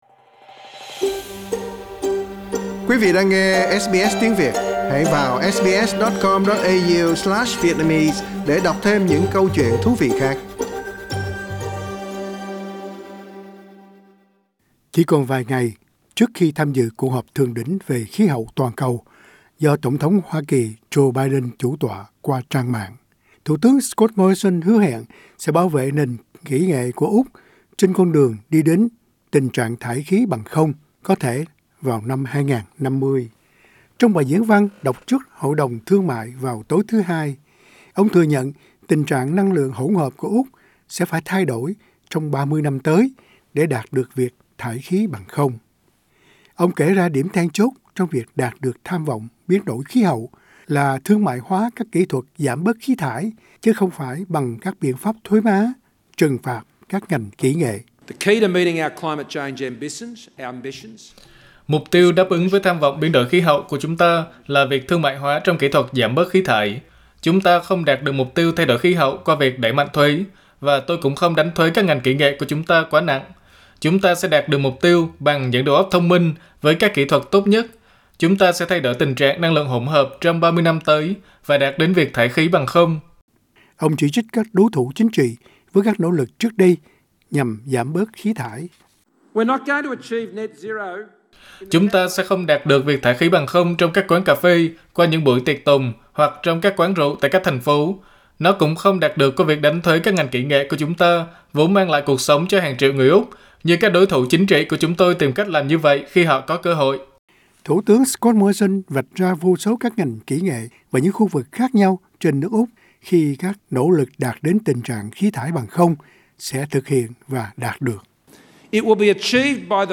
Scott Morrison speaking at the Business Council Source: SBS